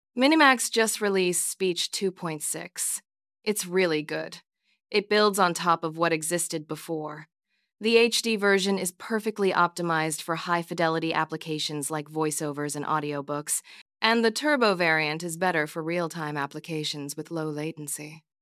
multilingual-tts text-to-speech voiceover
MiniMax Speech 2.6 HD delivers studio-quality multilingual text-to-audio on Replicate with nuanced prosody, subtitle export, and premium voices
"channel": "mono",
"voice_id": "Wise_Woman",
Generating speech with model speech-2.6-hd